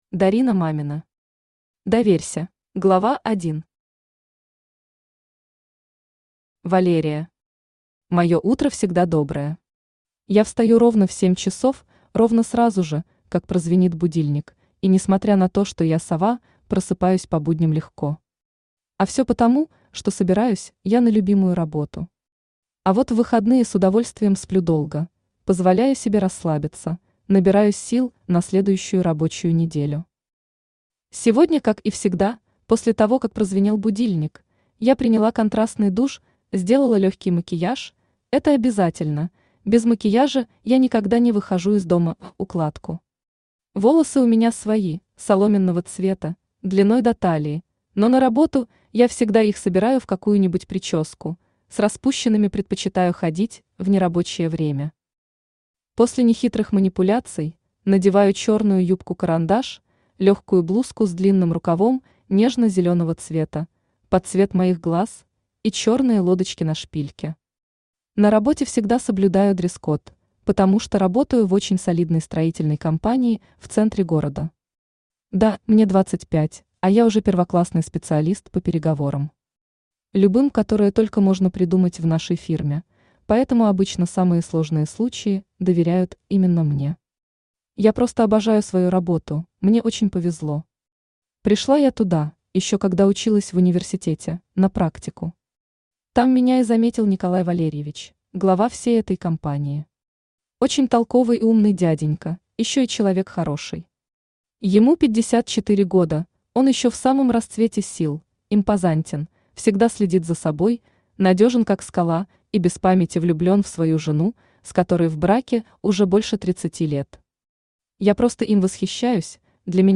Aудиокнига Доверься Автор Дарина Мамина Читает аудиокнигу Авточтец ЛитРес.